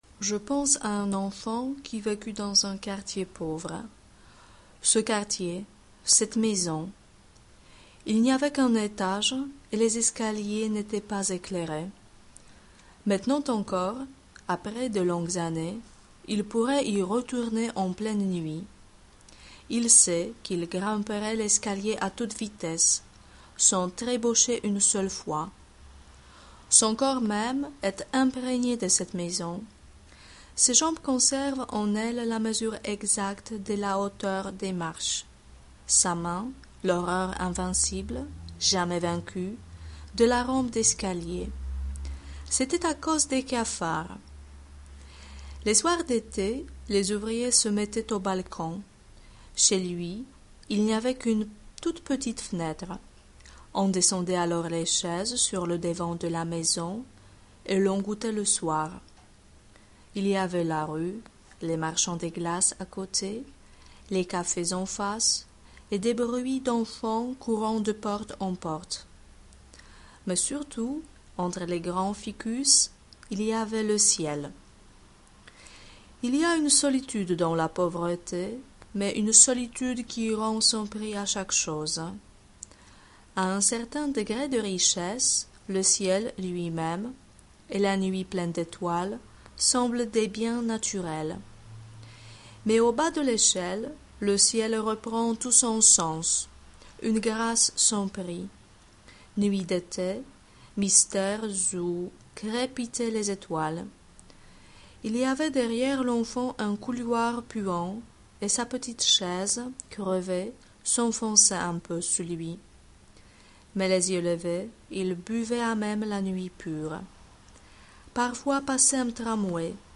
dyktando 2.mp3